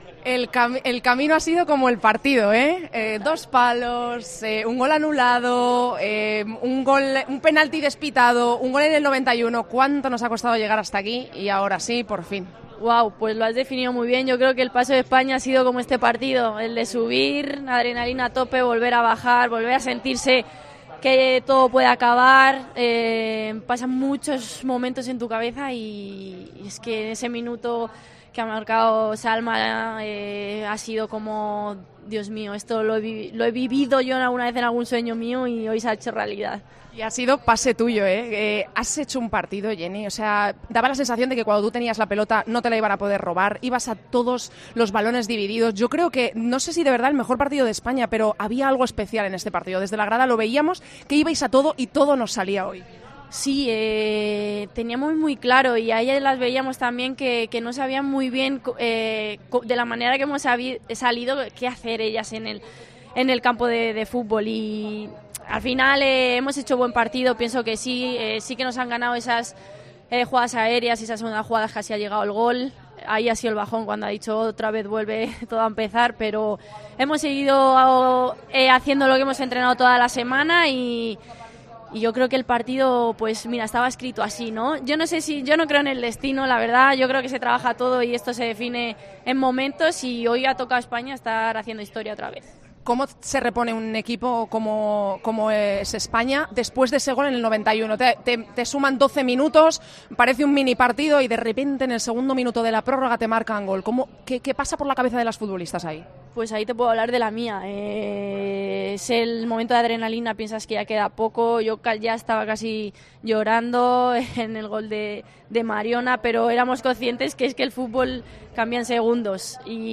La jugadora de la selección afirmó emocionada que la victoria ante Países Bajos se la dedica "a toda España".
Con Paco González, Manolo Lama y Juanma Castaño